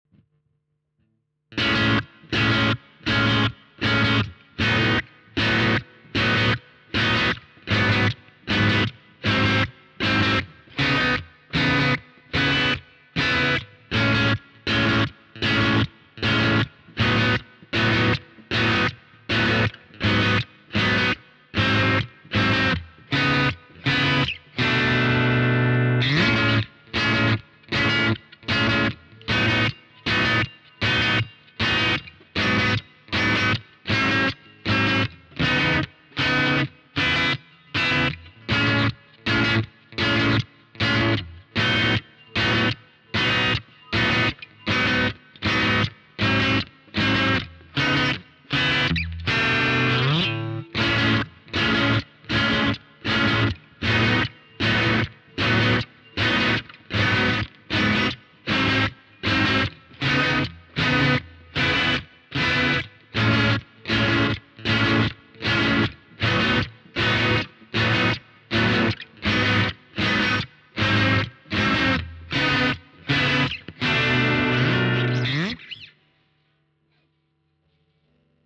so... meine Tele ist letzten Freitag angekommen, ich bin sehr glücklich dass ich dieses Modell am Ende bestellt habe, ich mag den Sound sehr sehr gern, ich habe einen schnellen Vergleichstest aufgenommen und hier angehängt, die erste Runde ist der Sound der Telecaster, die zweite ist meine Fender Jaguar (auch der Vintera Series) und am Ende noch eine Runde mit beiden in Stereo.
Der Twisted-Neck-PU tönt gut, nicht?
Ich habe übers Wochenende ein wenig gespielt und dabei keine Probleme gefunden, komischerweise im angehängten Soundtest kann man vielleicht ein Nachvibrieren hören, der ist aber bei der Jaguar und nicht bei der Tele, vielleicht muss ich da was an der Brücke tun.